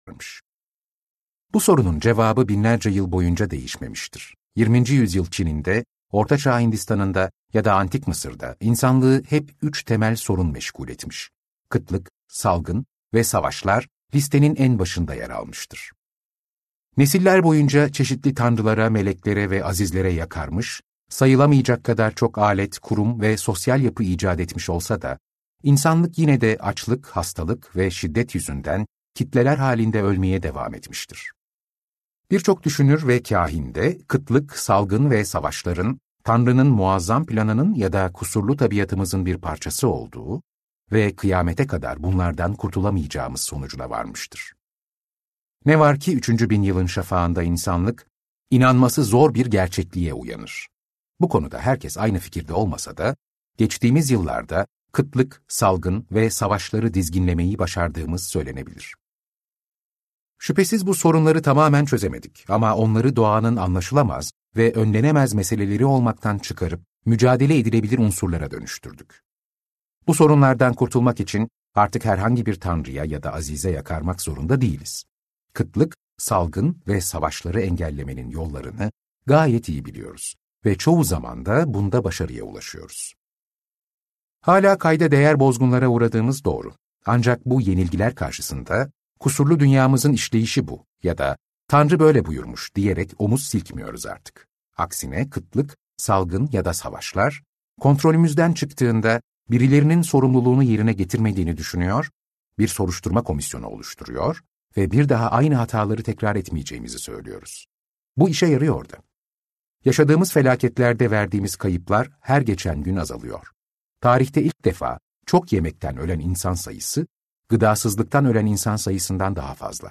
Homo Deus - Seslenen Kitap
Seslendiren